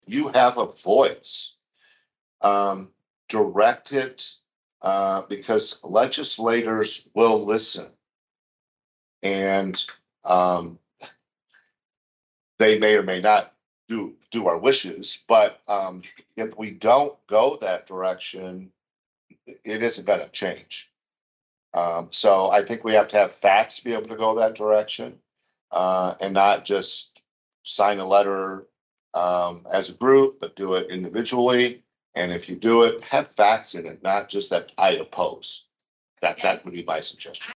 The supervisors continued to say that they can do very little to prevent the pipeline from being built.  Supervisor Steve Smith suggested that residents contact their Iowa legislators